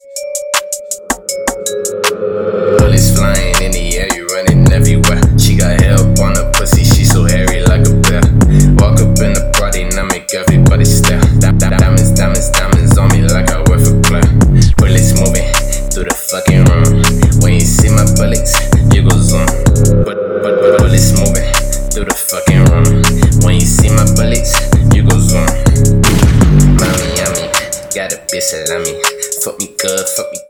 громкие
мощные басы
Trap
качающие
страшные
выстрелы
Cloud Rap